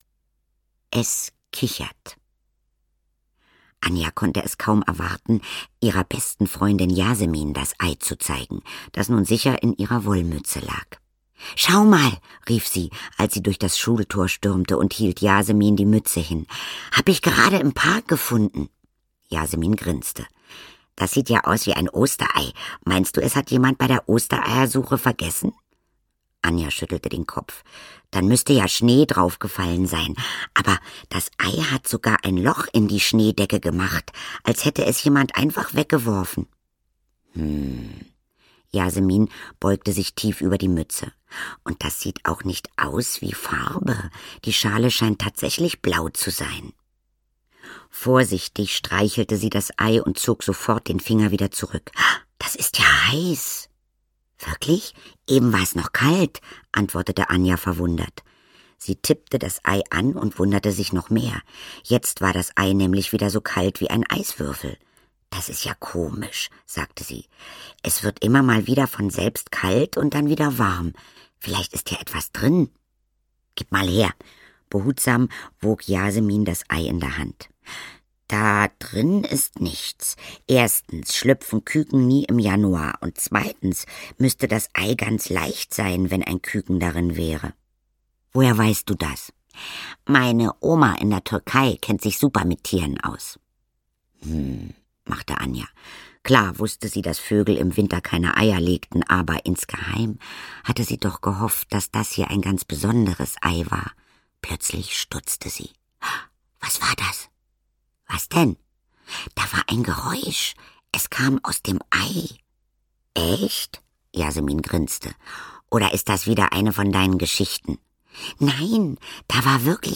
Der Drache aus dem blauen Ei - Nina Blazon - Hörbuch